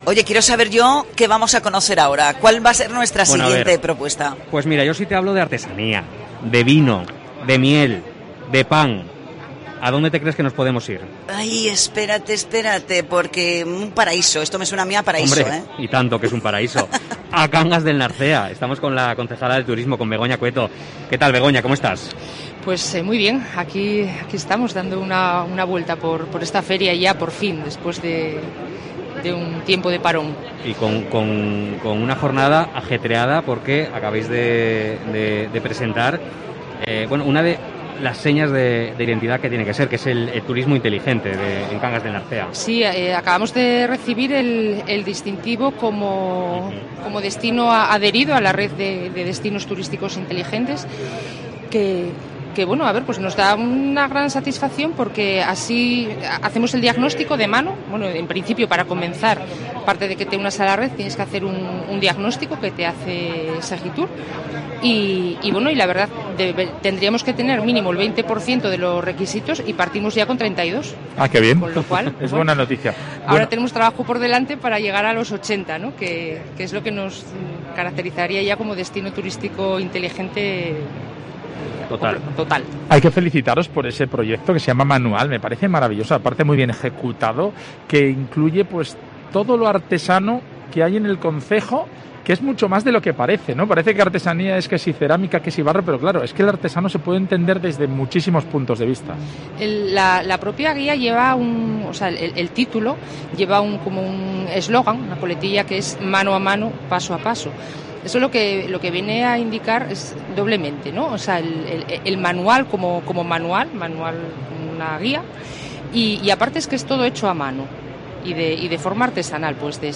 La concejala de Turismo, Begoña Cueto, se pasa por el programa especial de COPE Asturias desde Fitur antes de presentar, en la Feria del Turismo, su guía 'Manual'
Fitur 2022: Entrevista a Begoña Cueto, concejala de Turismo de Cangas del Narcea